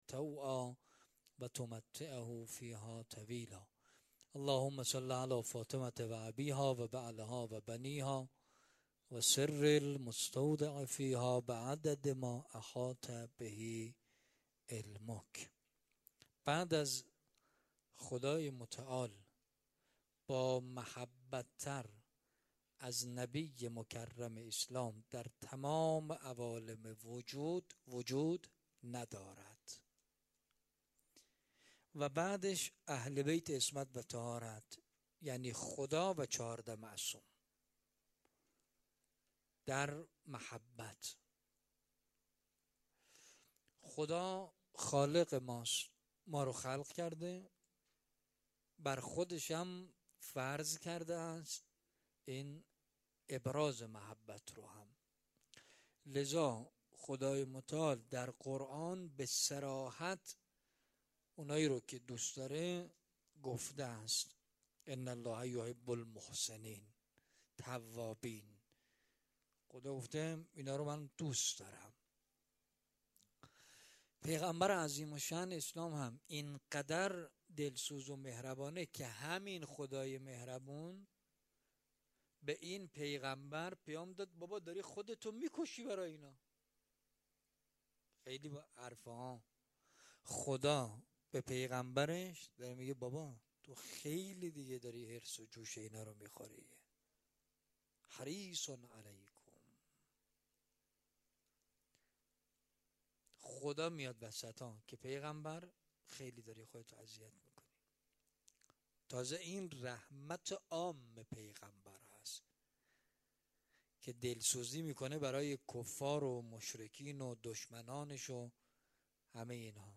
سخنرانی
مراسم مناجات شب بیست و چهارم ماه رمضان پنجشنبه ۱۶ اردیبهشت ۱۴۰۰ حسینیه ریحانة‌الحسین(س)